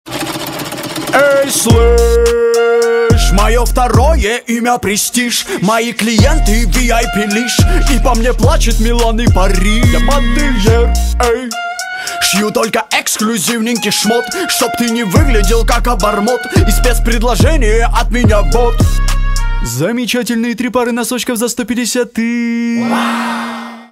Рэп и Хип Хоп
пародия